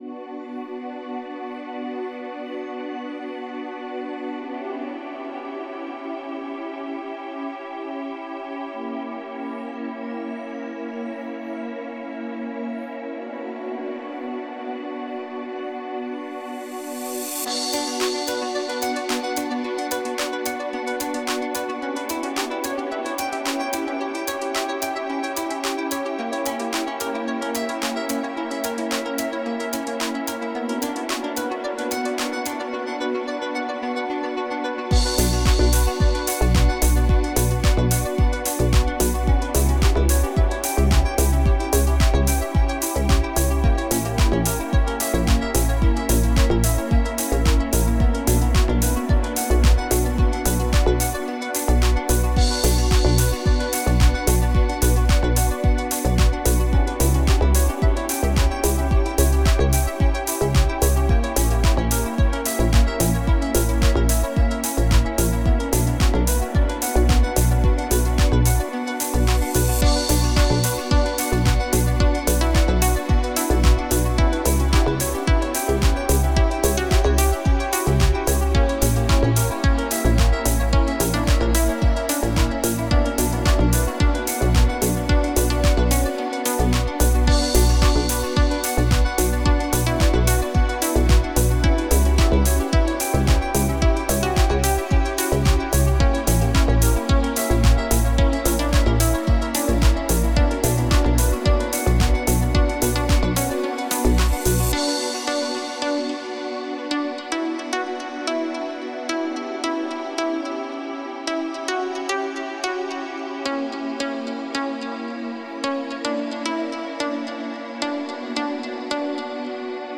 Ну, я бы нк сказала, что мелодия такая уж, прям, минорная. По мне - что-то типа нейтрального такого, фона.
Есть действительно что-то фаталистическое в ней.